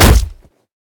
fixed kick sounds
flesh3.ogg